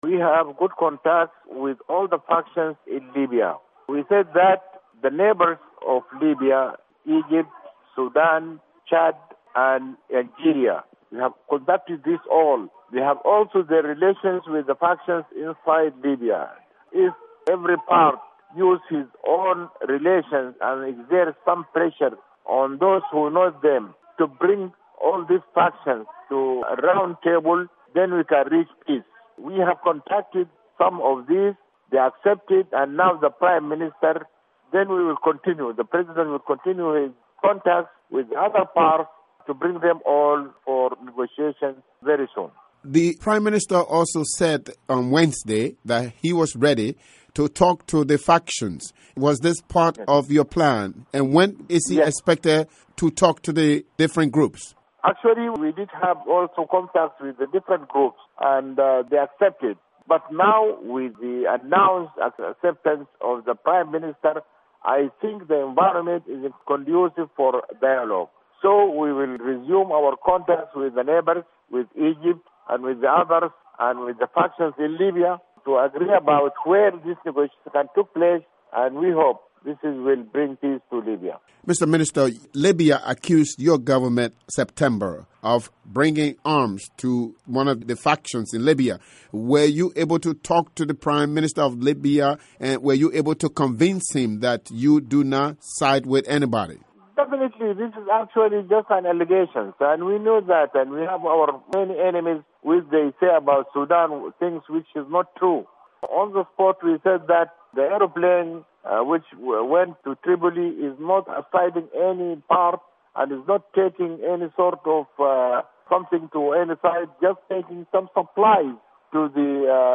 interview with Sudan information minister